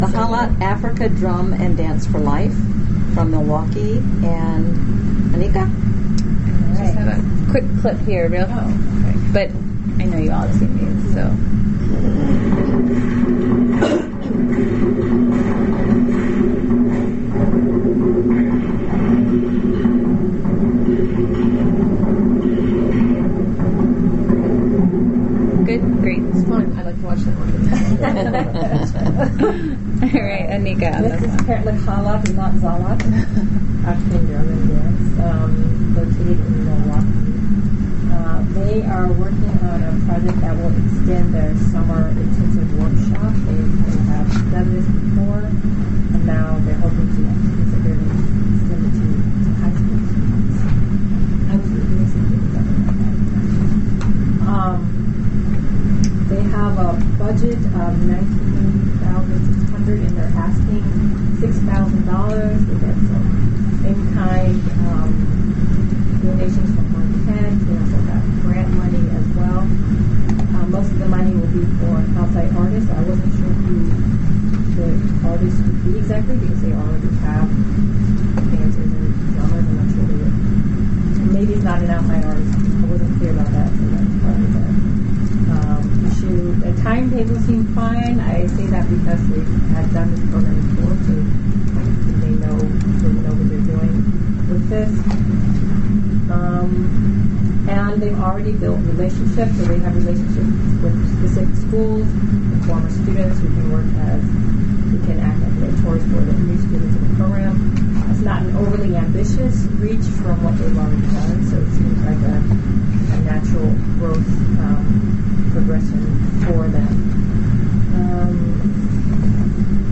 listen to the panel review of this organization